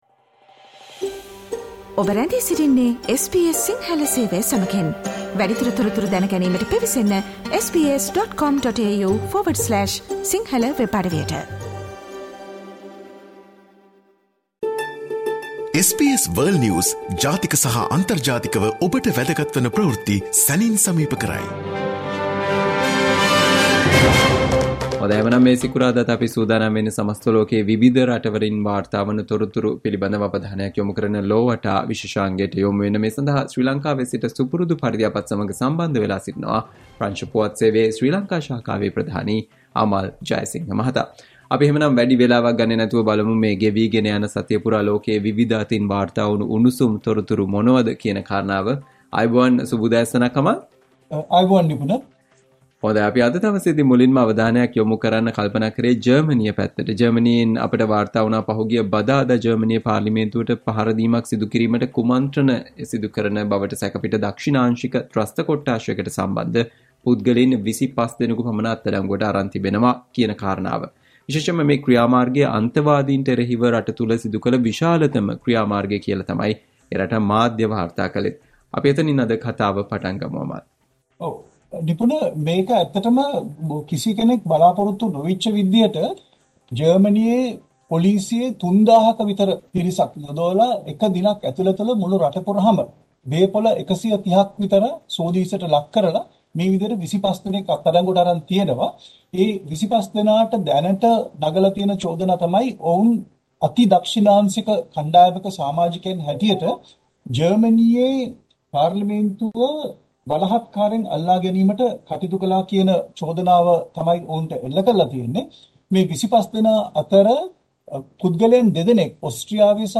listen to the SBS Sinhala Radio weekly world News wrap every Friday